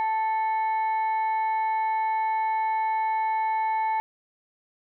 例: バイオリンの波形を Fourier 展開してみると
このデータで合成した 音は
これ ですが, さらに音の立ち上がり, 減衰も工夫しないとそれらしくならないようです.
genwave5-violin.wav